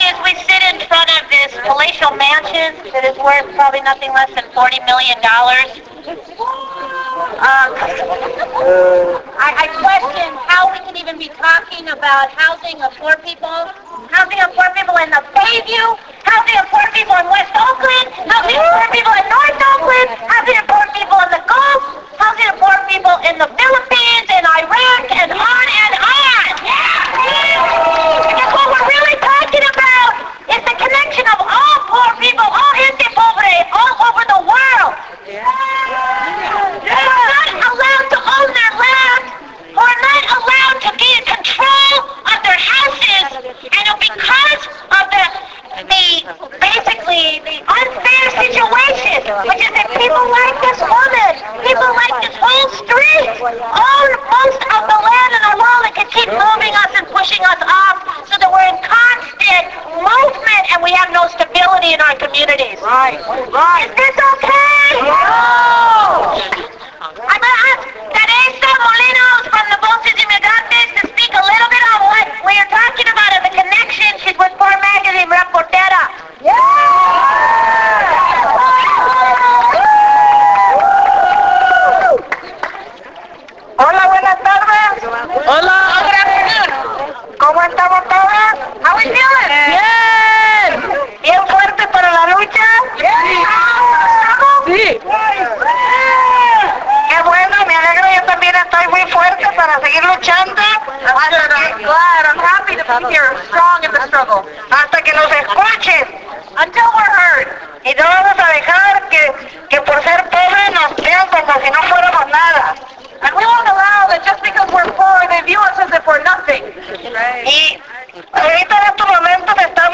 On top a hill overlooking San Francisco's bay, hundreds of us came together in an effort to hold Senator Dianne Feinstein accountable, stand in solidarity with people of the Gulf Coast and the world, mourn those lost, and celebrate resistance.